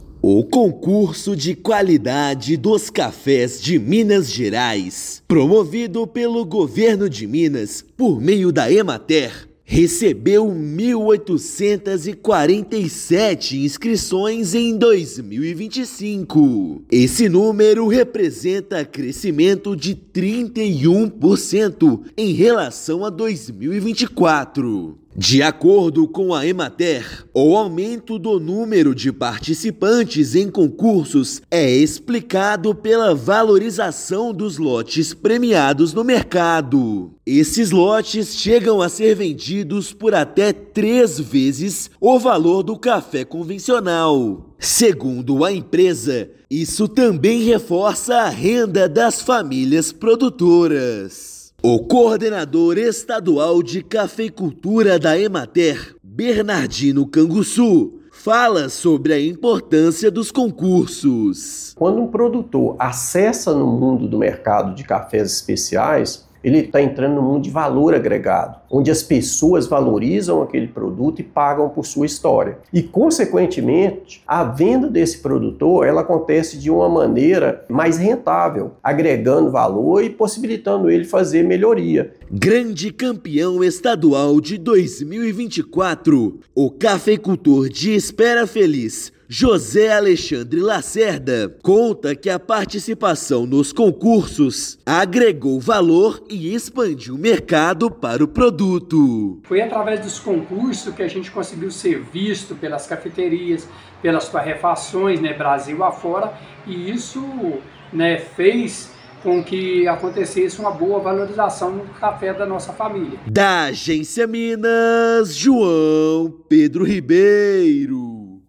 Em 2025, competição estadual bateu recorde de inscrições e estado se consolida como referência nacional em cafés especiais. Ouça matéria de rádio.